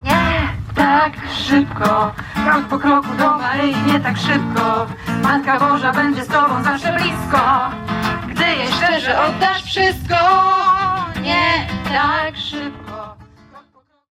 – tu w wykonaniu pielgrzymki z Wrocławia.